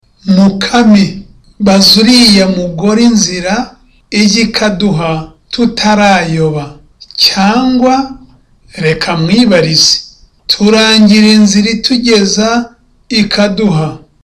(Seriously)